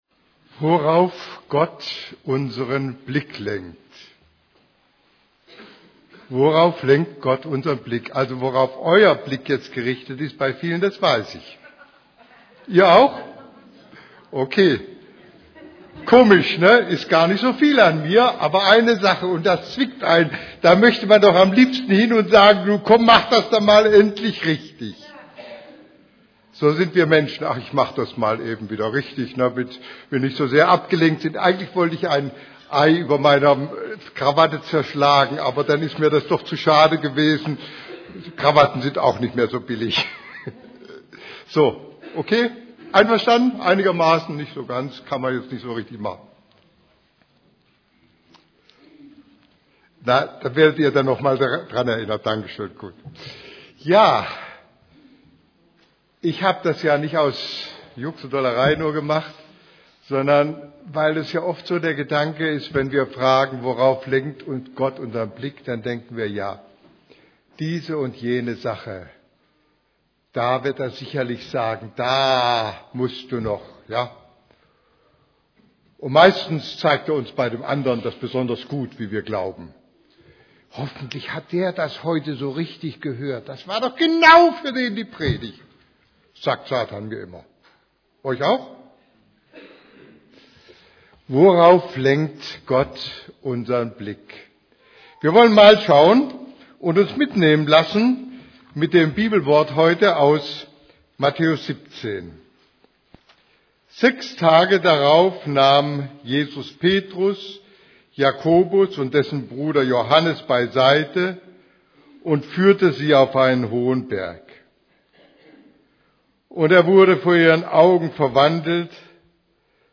> Übersicht Predigten Worauf Gott unseren Blick lenkt Predigt vom 14.